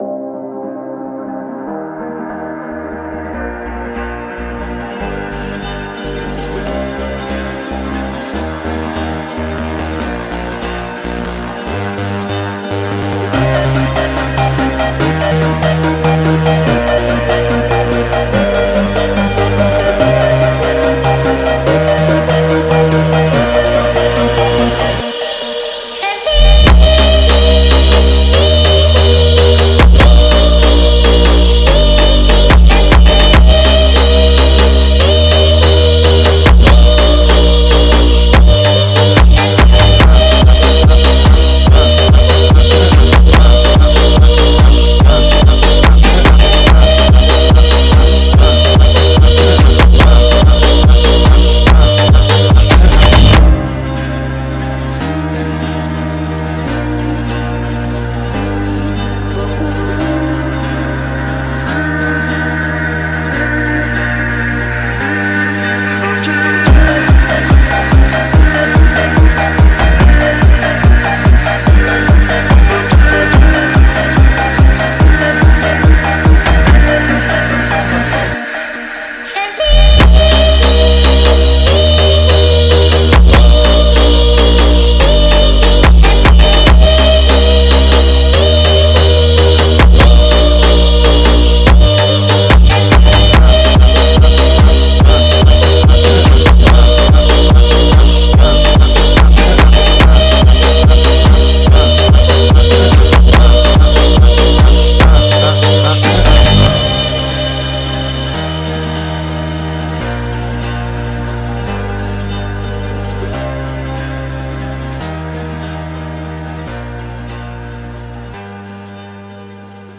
Phonk remix